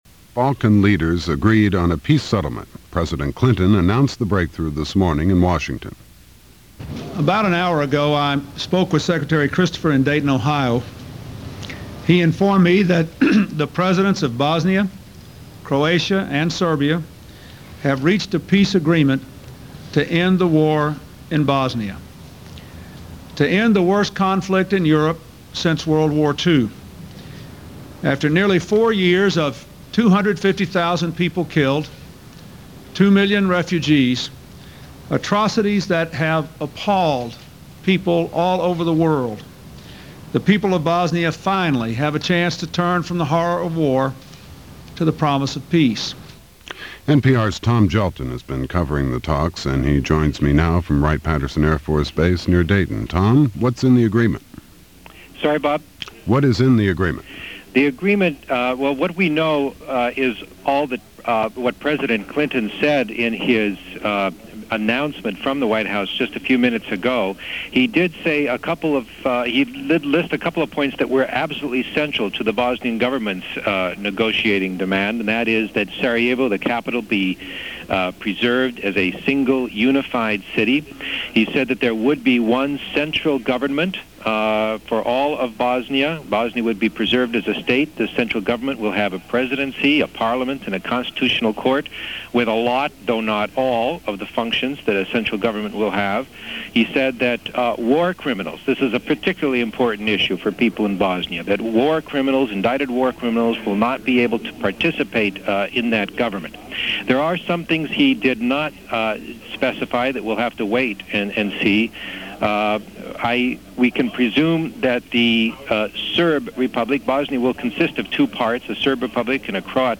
Dayton: At Long Last, A Start - November 21, 1995 - news for this day from NPR's Morning Edition program - November 21, 1995